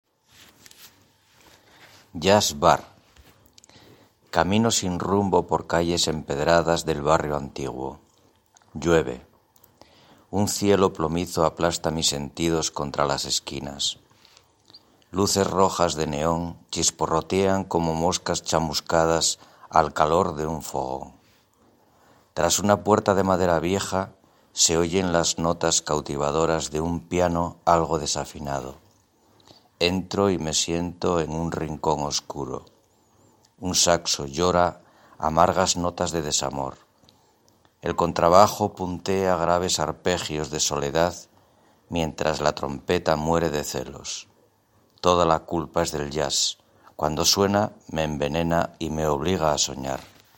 Publicado en Poemas recitados | Deja un comentario